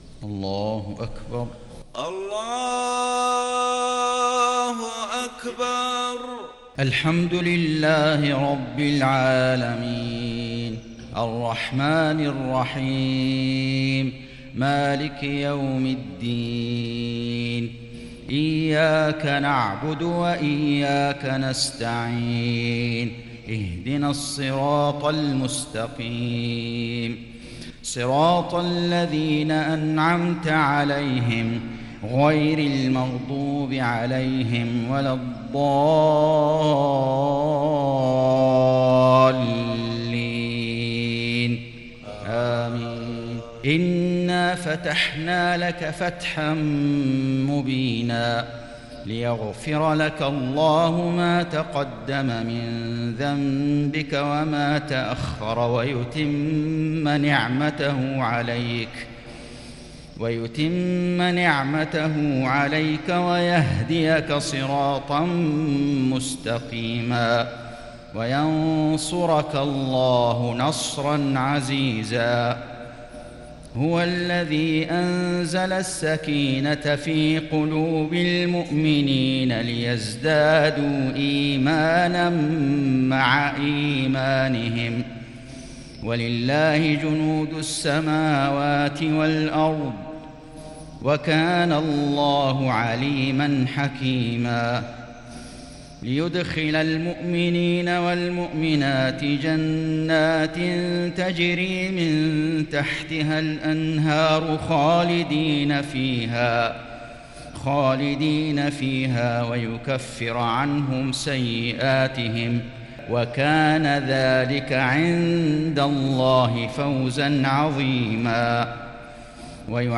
صلاة المغرب
تِلَاوَات الْحَرَمَيْن .